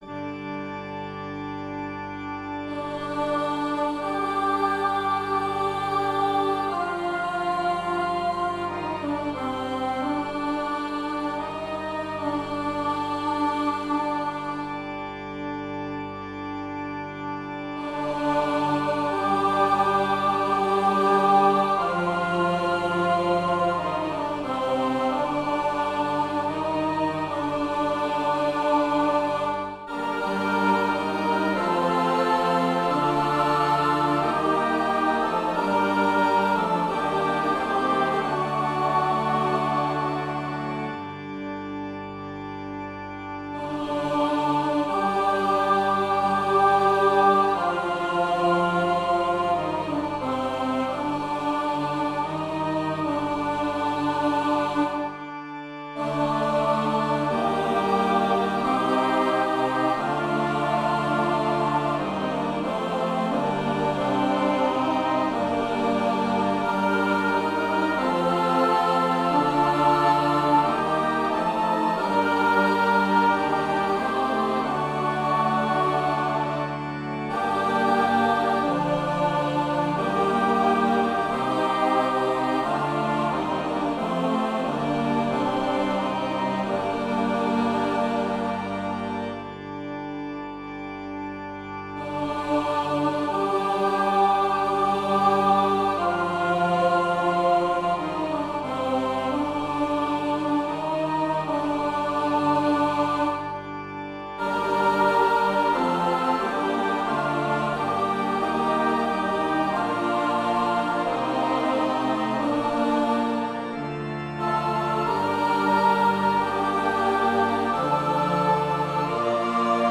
Voicing/Instrumentation: SATB , Organ/Organ Accompaniment